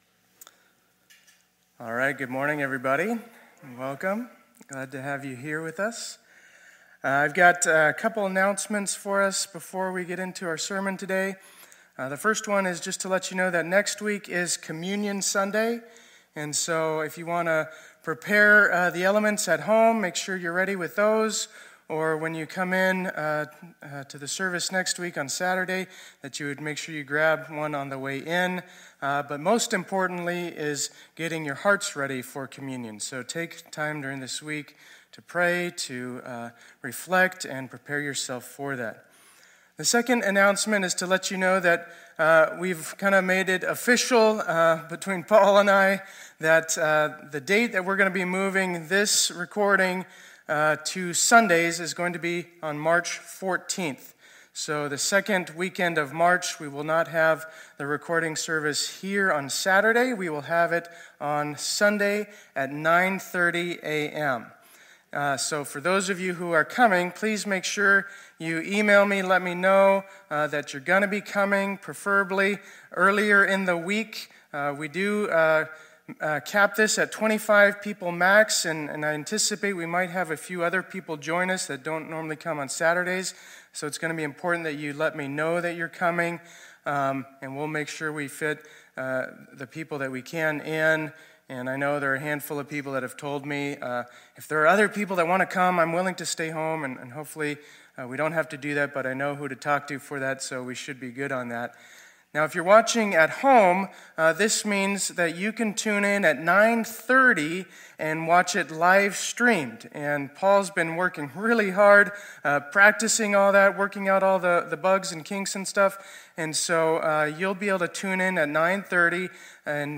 2021-02-28 Sunday Service | Faith Community Church